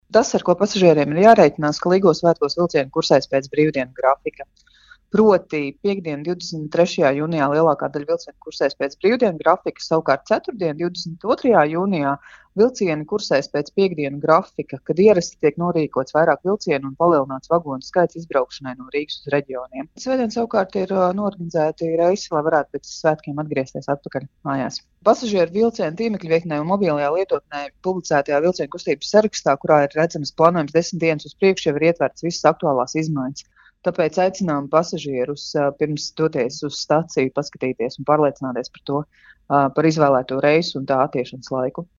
RADIO SKONTO Ziņās par vilcienu grafiku Līgo svētkos